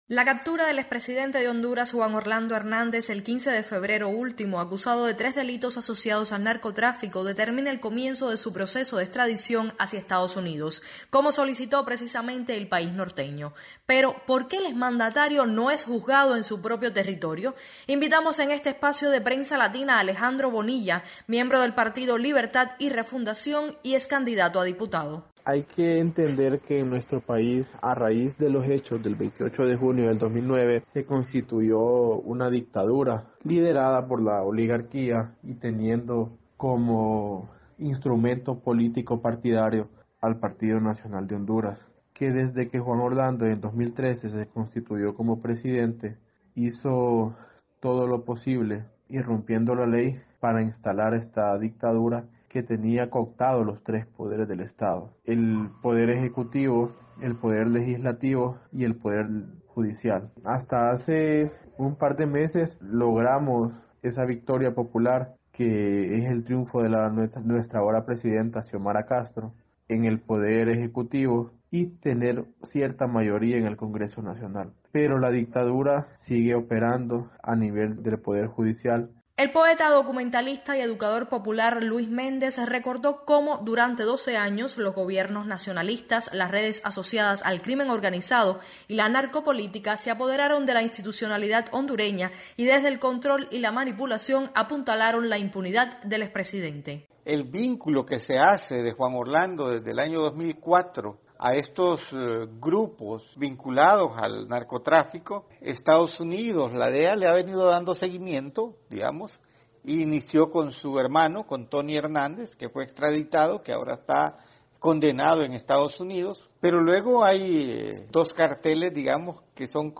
desde Honduras